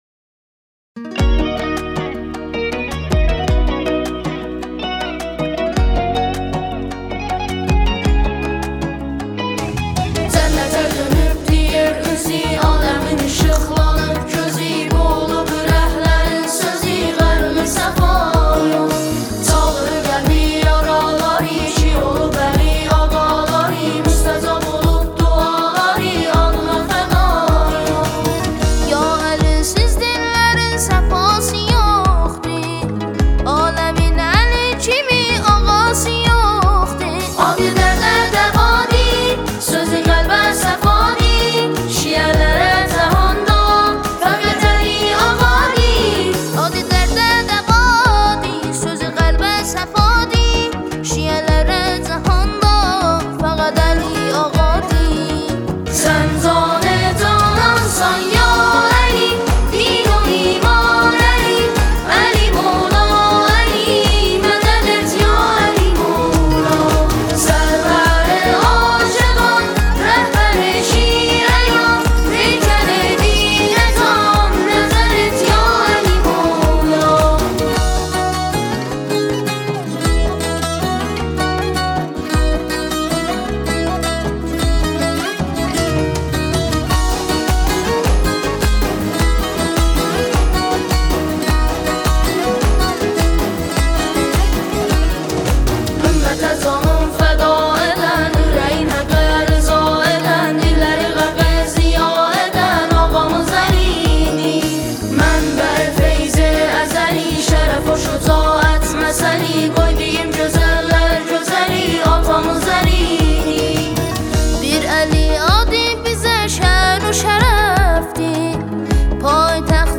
فایل باکلام